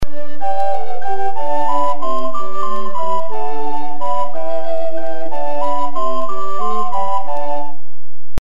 Recorders Sound Clips
Although the four members of a recorder consort are usually soprano (descant), alto (treble), tenor and bass - heard together on the sound clip which can be downloaded above - the photograph also features a smaller sopranino recorder, ideal in earlier times for providing dance music out of doors because of its high pitch and loud tone.